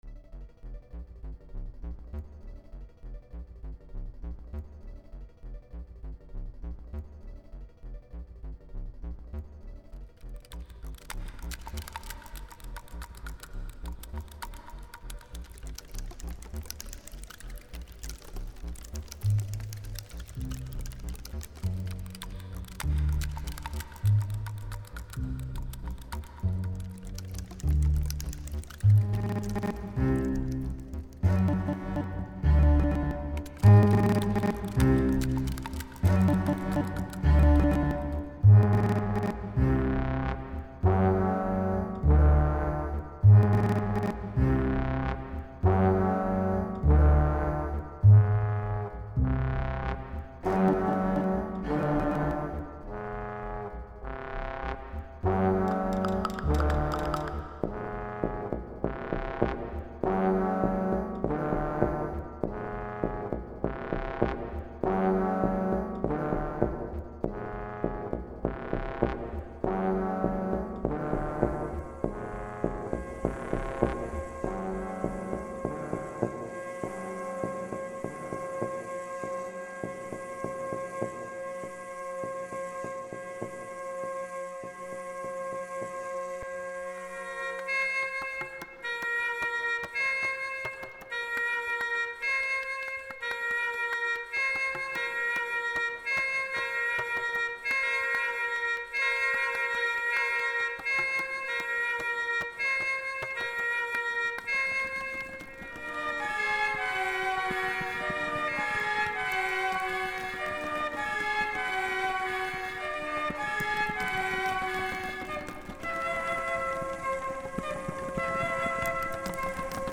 Settimino y Live Electronic
(Trompeta – Oboe – Clarinete – Flauta – Trombón – Violonchelo – Contrabajo)
• Utilizar solo las notas DO-RE-MI-FA#-SOL#-LA# (escala hexatónica)
Sucesivamente he arreglado la partitura para 7 instrumentos (Trompeta – Oboe – Clarinete – Flauta – Trombón – Violonchelo – Contrabajo), grabado los incisos con músicos y procesado los sonidos grabados.